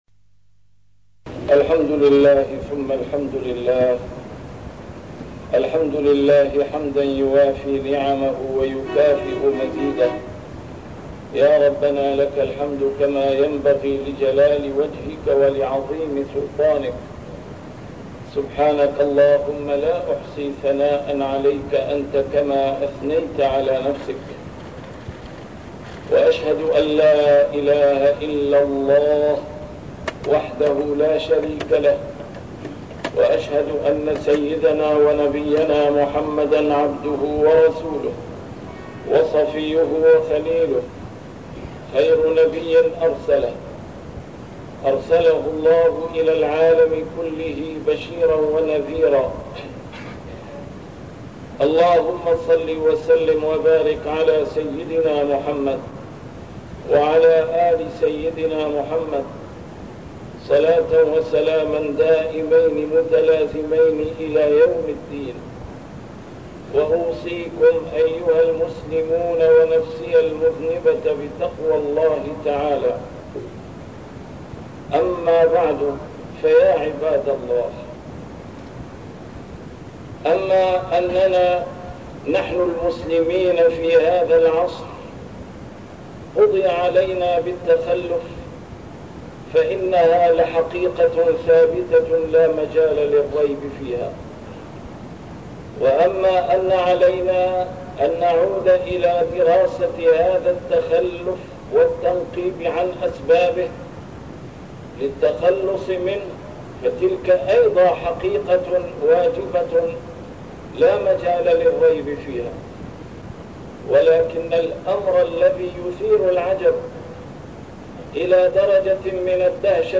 A MARTYR SCHOLAR: IMAM MUHAMMAD SAEED RAMADAN AL-BOUTI - الخطب - الوازع الديني سبيلنا إلى التخلص من التخلف